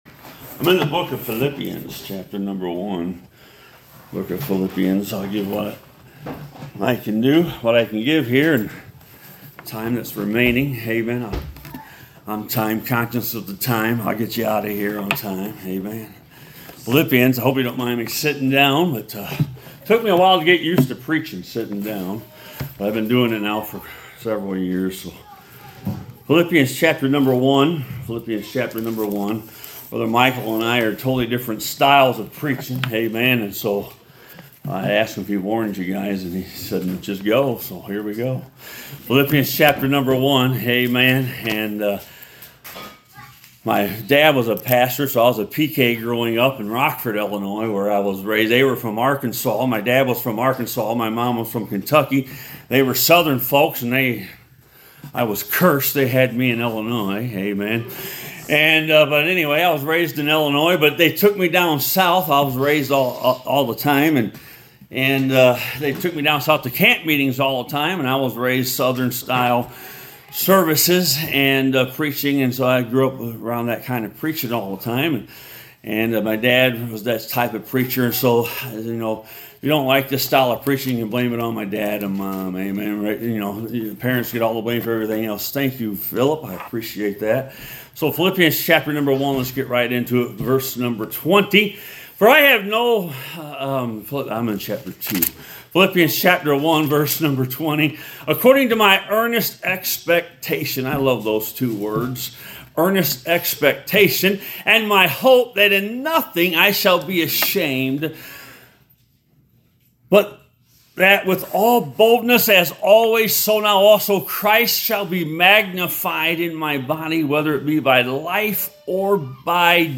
Passage: Philippians 1:20 Service Type: Sunday Morning